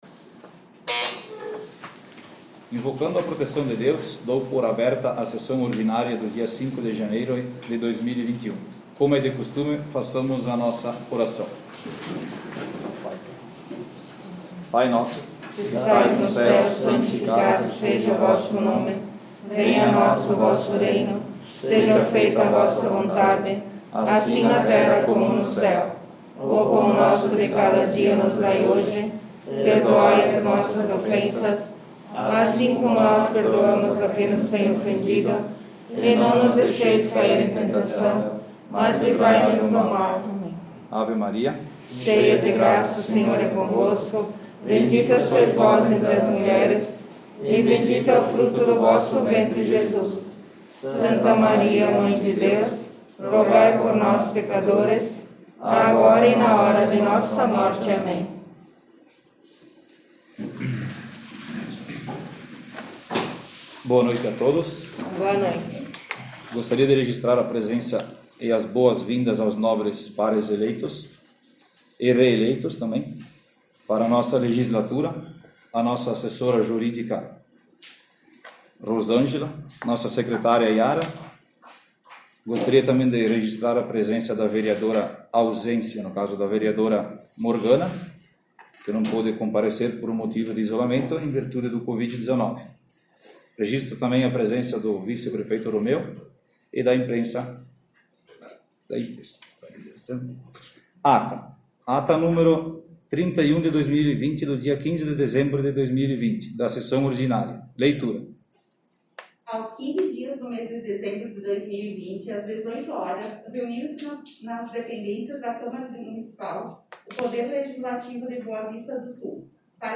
Sessão Ordinária 05/01/2021